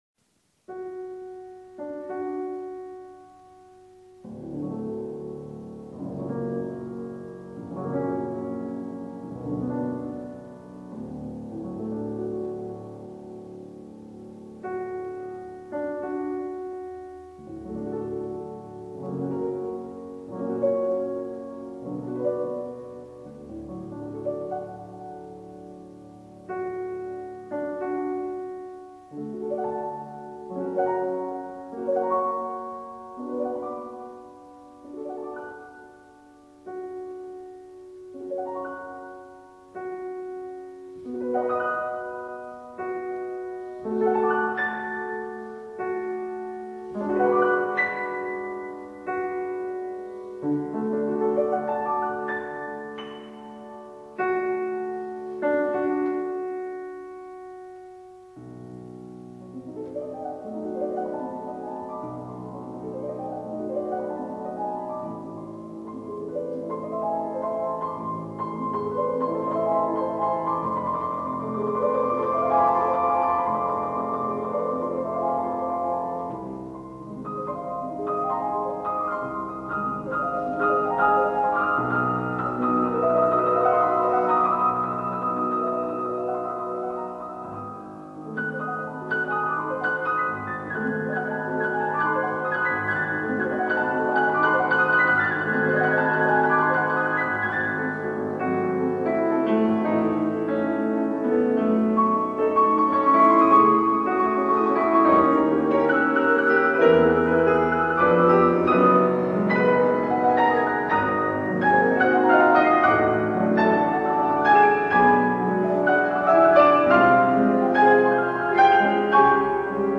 Suite No.1 for two pianos, Op.5
Piano